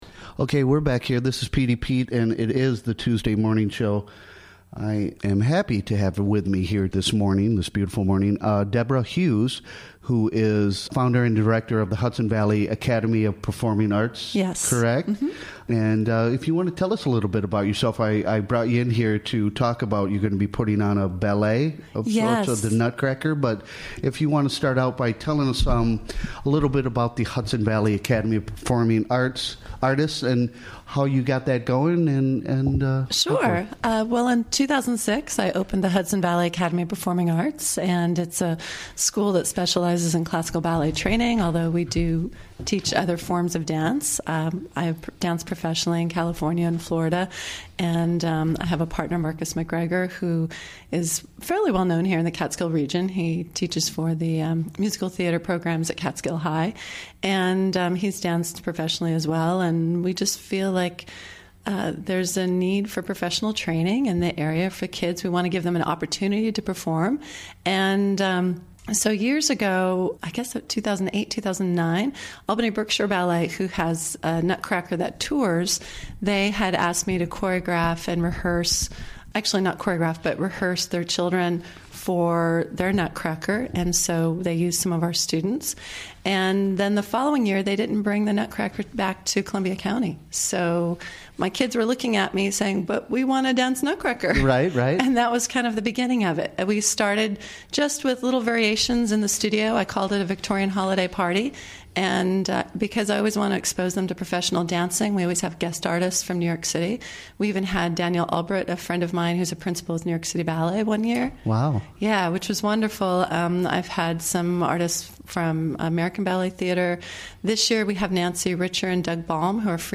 Recorded during the WGXC Morning Show of Tues., Dec. 5, 2017.